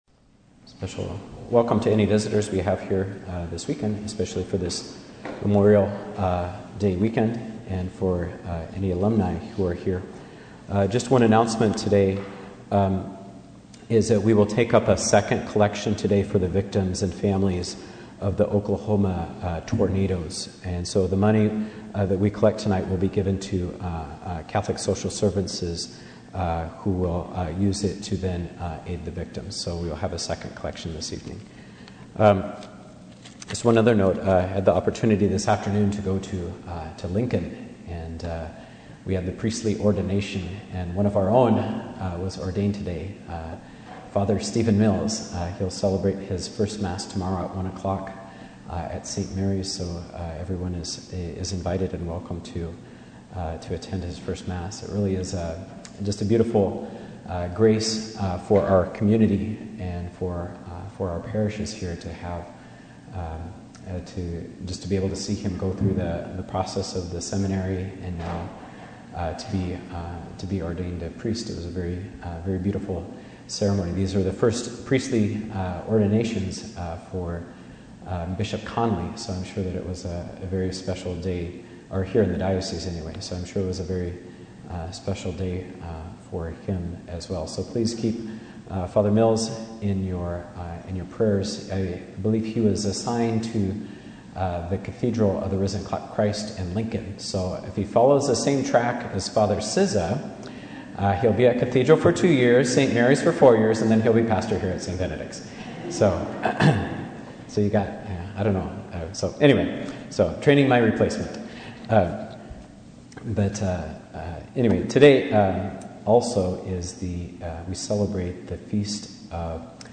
Homilies from 2013